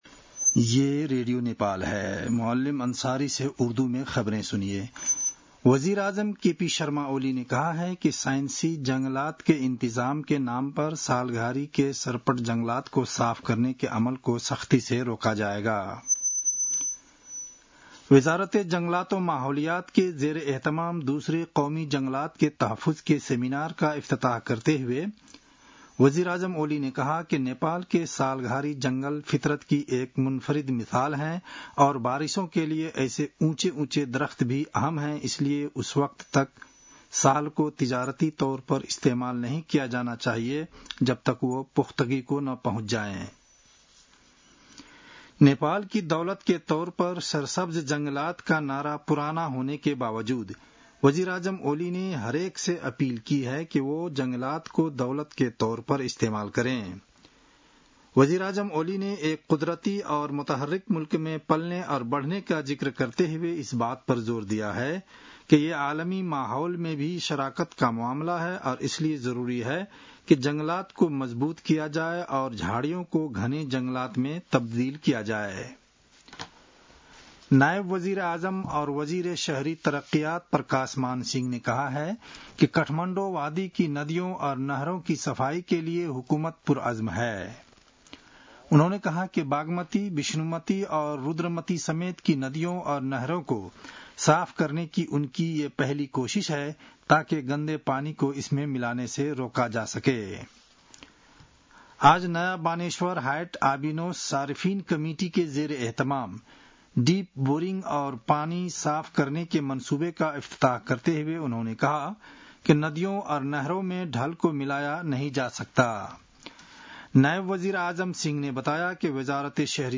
उर्दु भाषामा समाचार : २७ वैशाख , २०८२